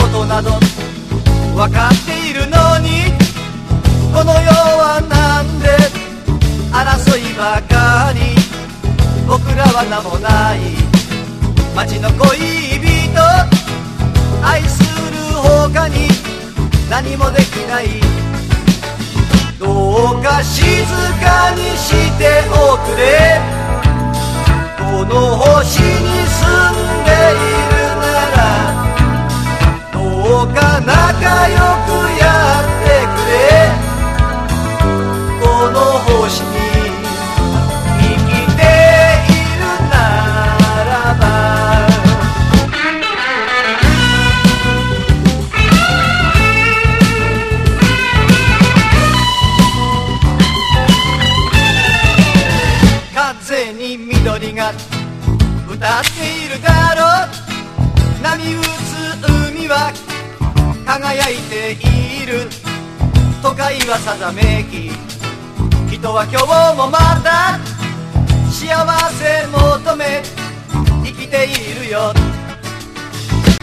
ELECTONE LOUNGE / JAPANESE GROOVE
独特な和モノ・エレクトーン・グルーヴ！